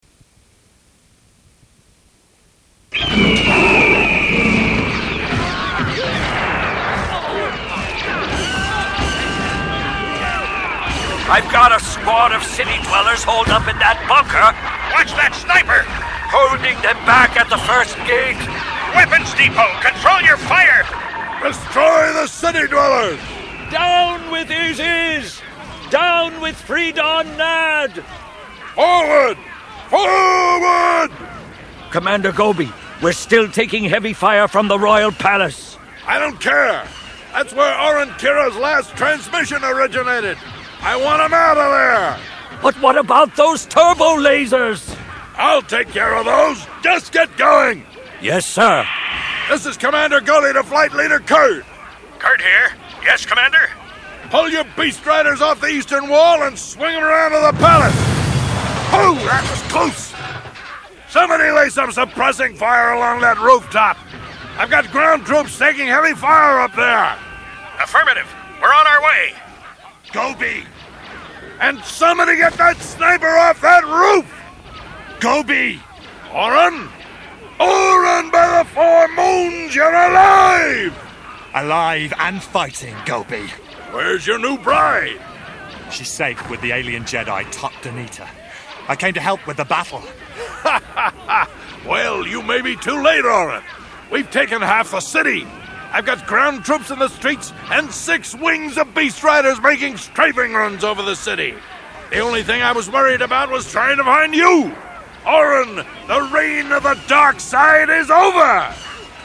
File File history File usage TotJaudioclip.ogg (file size: 1.68 MB, MIME type: application/ogg ) Summary File:TotJaudioclip.ogg Information Description "Tales of the Jedi" sample, 114 sec. Source Tales of the Jedi , audio book, part 2 Original designer / artist Written by John Whitman , based on Tom Veitch 's script Licensing This is an Ogg Vorbis sound sample.